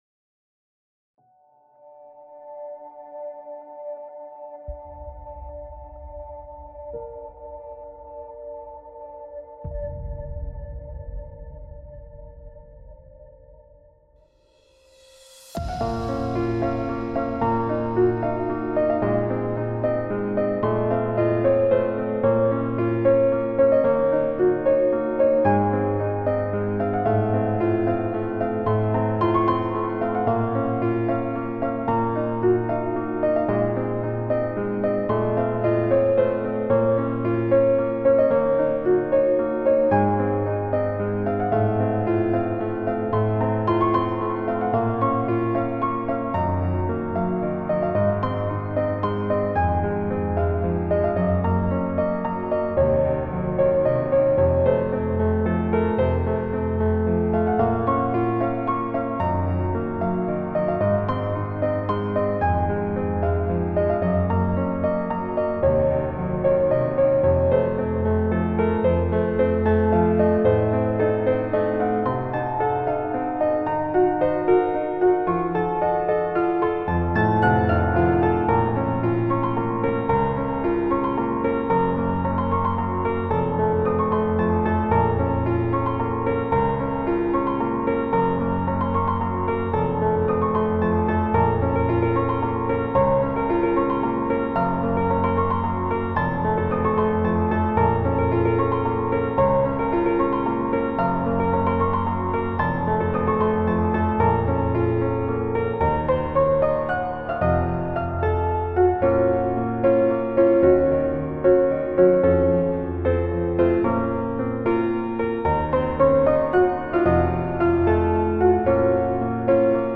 Cet audio n’est pas conçu pour la relaxation.